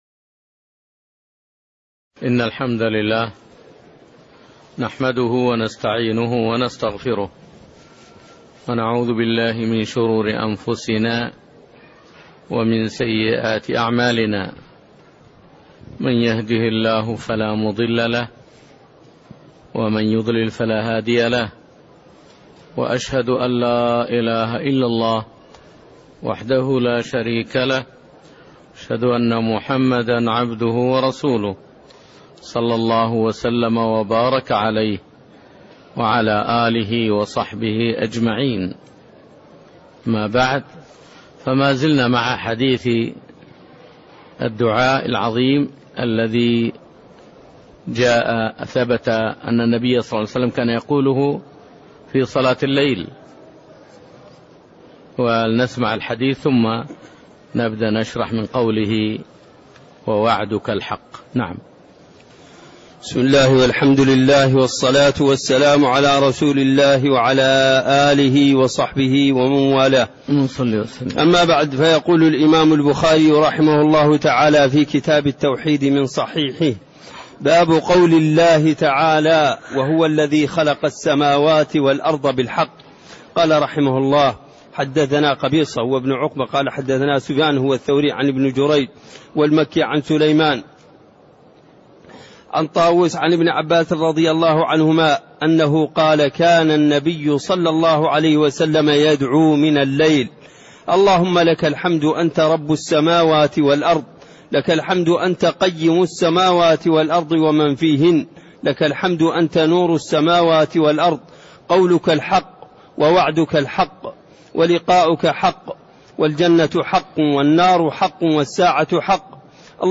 تاريخ النشر ١٣ صفر ١٤٣٣ هـ المكان: المسجد النبوي الشيخ